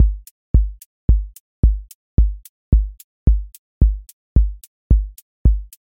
QA Listening Test house Template: four_on_floor
• voice_kick_808
• voice_hat_rimshot
• motion_drift_slow
A longer-form house song study with multiple sections, evolving patterns, and clear internal edits within repeated grooves.